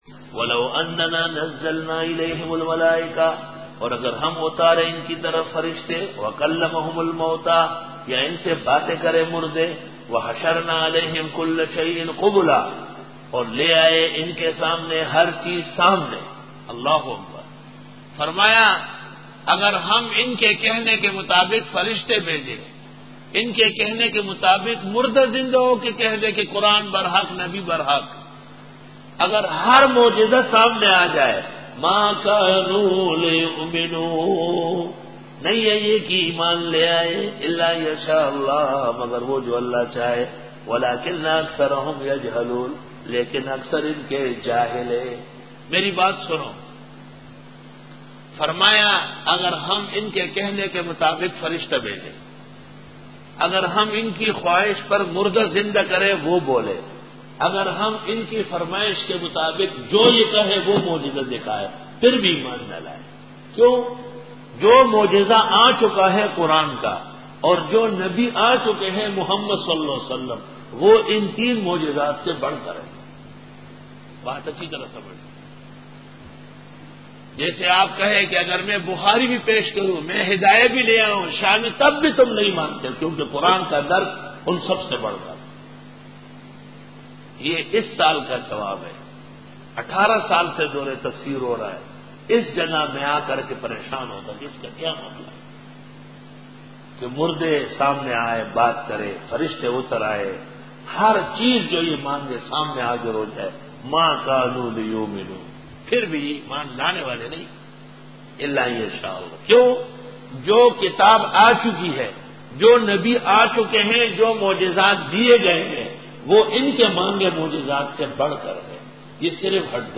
سورۃ الانعام رکوع-14 Bayan